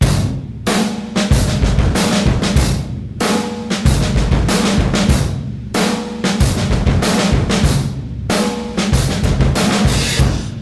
sample2 - Autolux’s Turnstile Blues intro drum loop